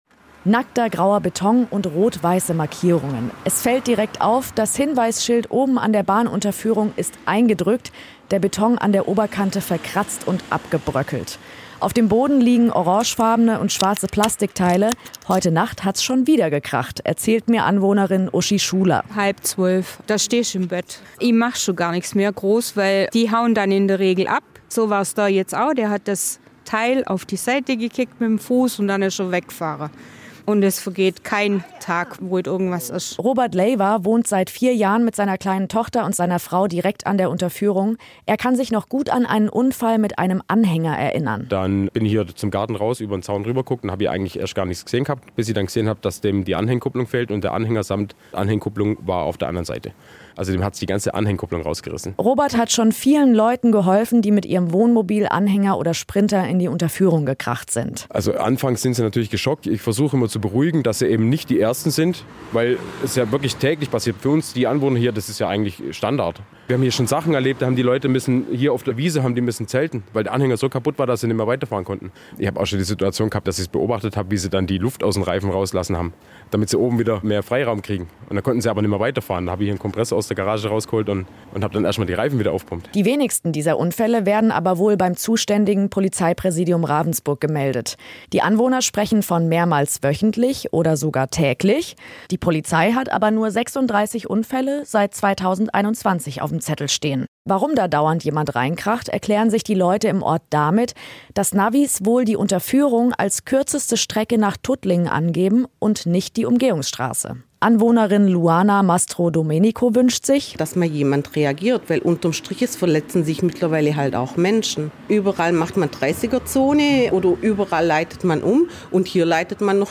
Nachrichten Anwohner erzählen: „Halb zwölf nachts, da stehst du im Bett!“